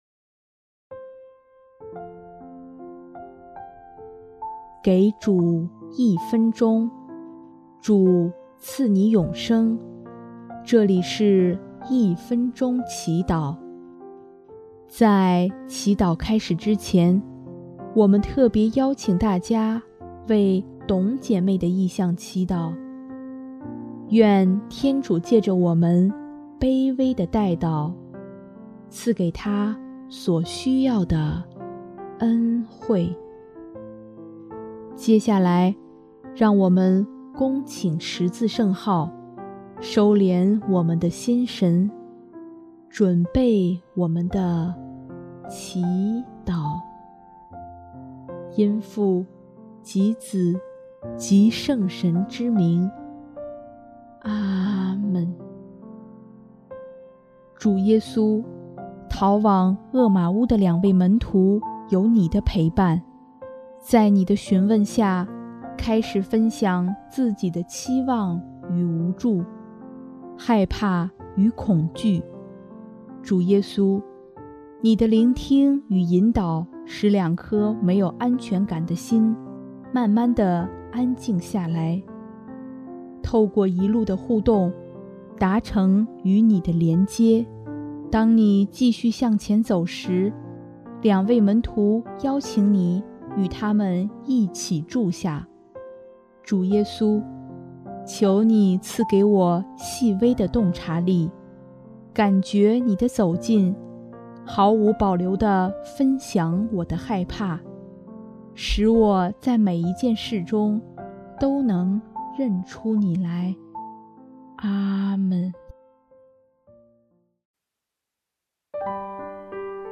【一分钟祈祷】|4月3日 痛苦中的连接